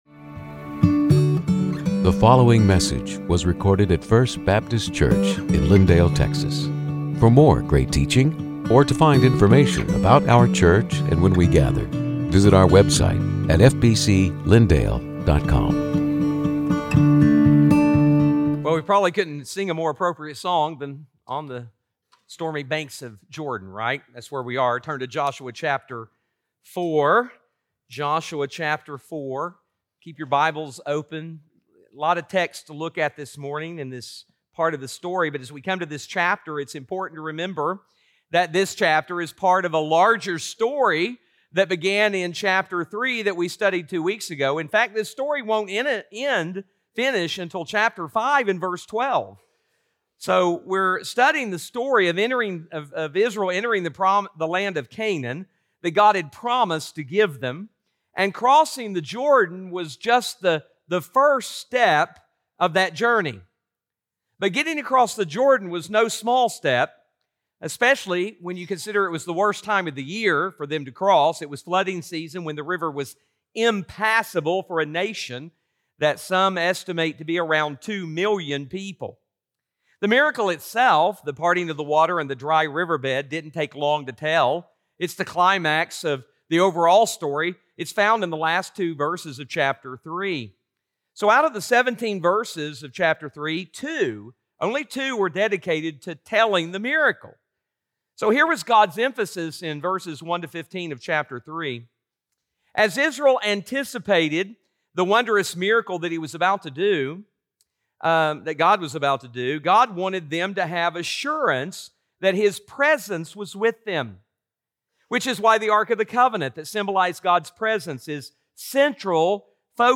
Sermons › Joshua 4:1-24